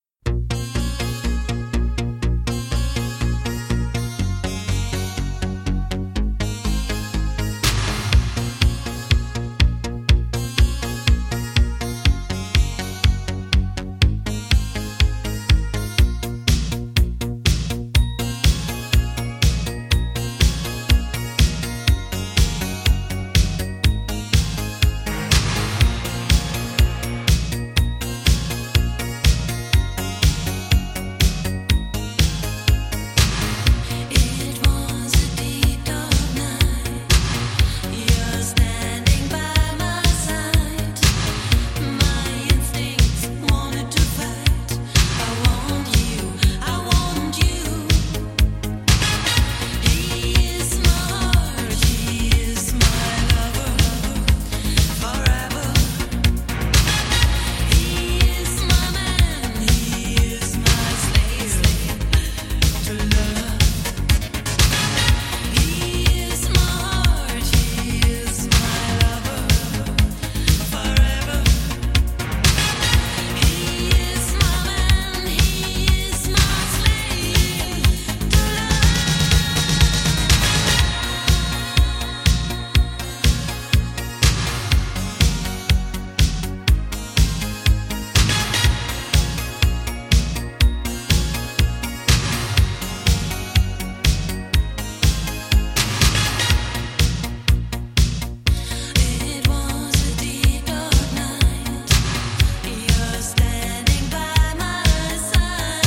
デケデケと迫るシンセベースや切なげな歌唱